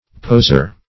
Poser \Pos"er\, n.